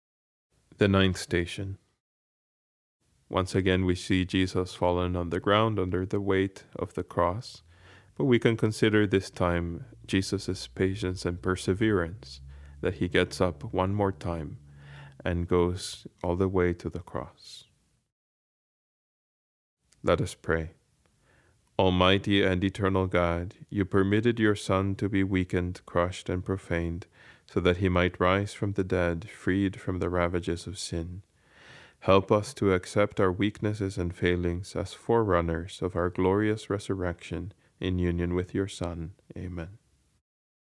Station 9: Prayer
Baltimore-Museum-of-Art-Station-9-prayer.mp3